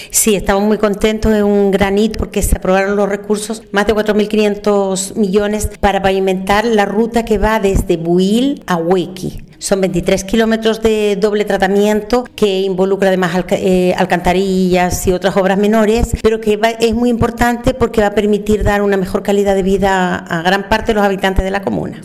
Clara Lazcano, alcaldesa de Chaitén, expresó que serán más de 1.200 los habitantes beneficiados.